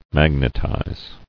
[mag·net·ize]